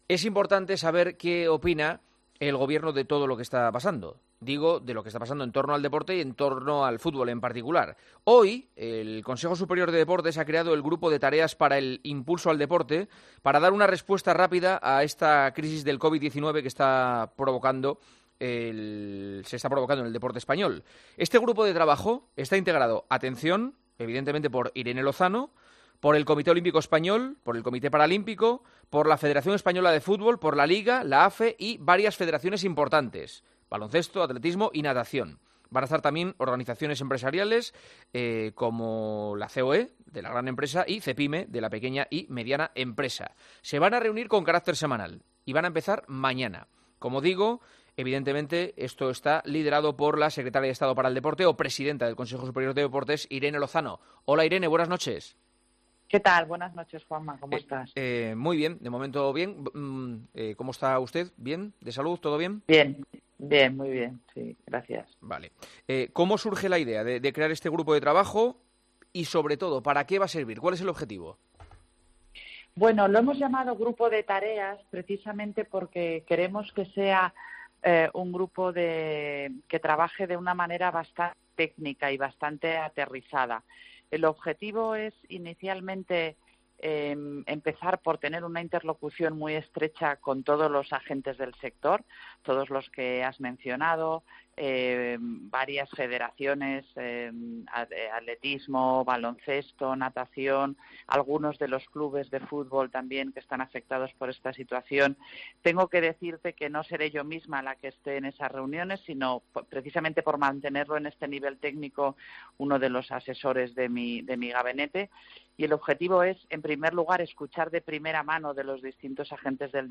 AUDIO - ENTREVISTA A IRENE LOZANO, EN EL PARTIDAZO DE COPE